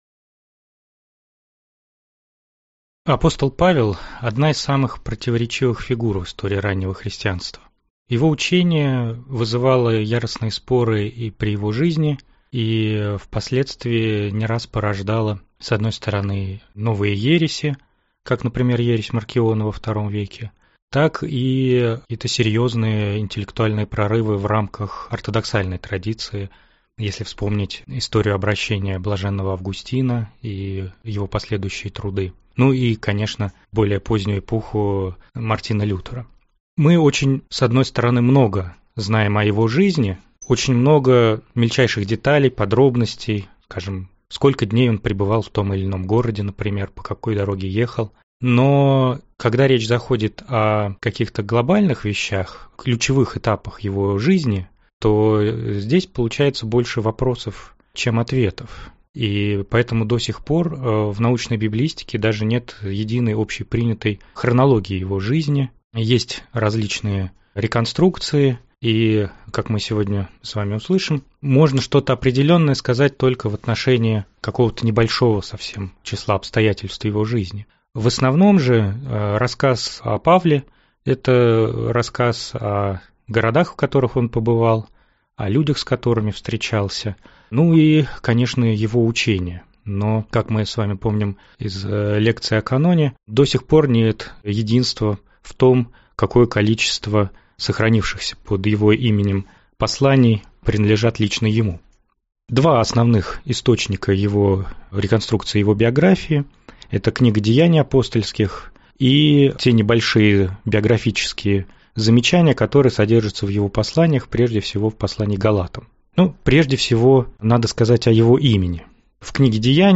Аудиокнига Апостол Павел, его жизнь и учение.